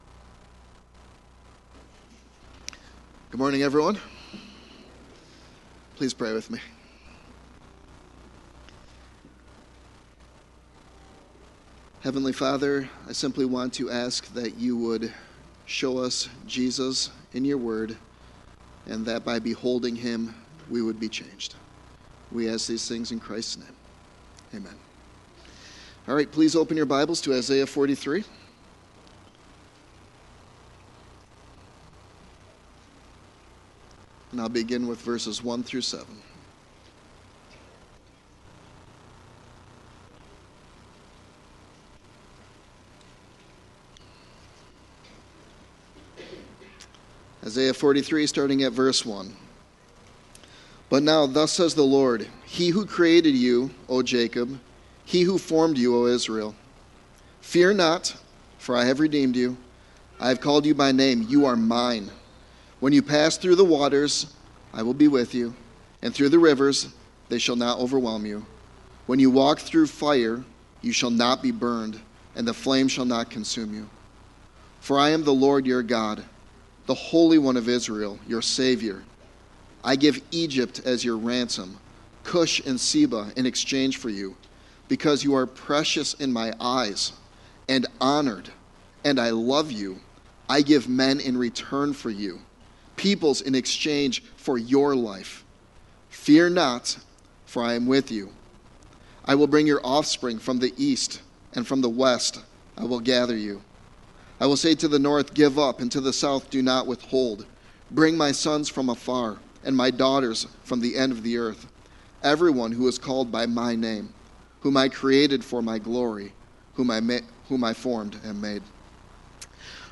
Sermon Text